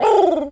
pigeon_call_angry_17.wav